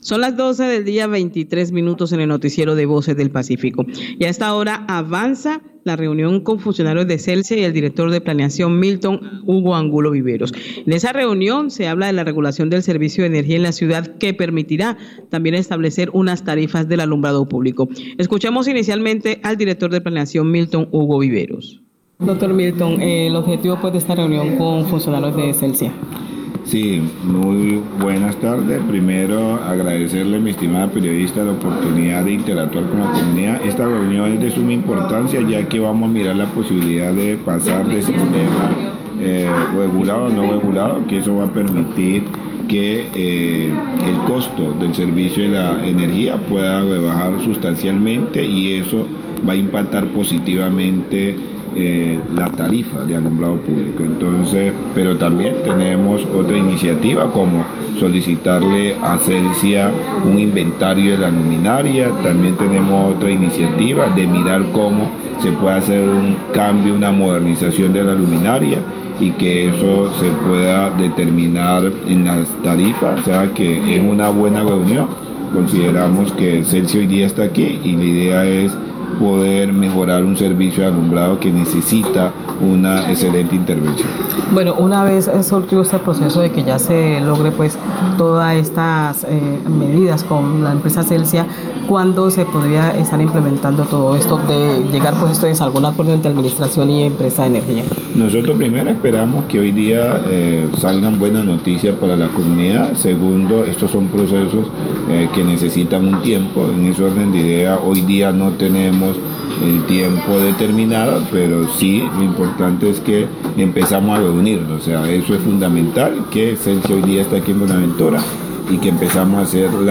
Radio
Director de la Oficina de Planeación de Buenaventura, Milton Angulo,  habla de la reunión realizada con funcionarios de la empresa Celsia, donde se dialogó sobre la regulación del servicios de energía y las hoja de ruta que se busca establecer las tarifas de alumbrado público.